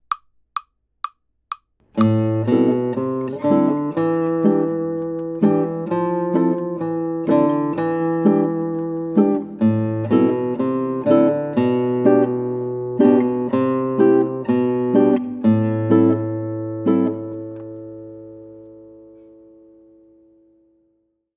Pie in the Sky | Melody and chords (1X)